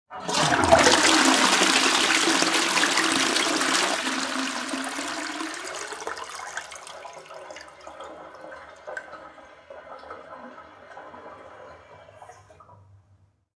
Scarico del water (W.C.) Sciaquone
Rumore dell'acqua di scarico del water con riempimento cassetta d'acqua.
ToiletFlush.mp3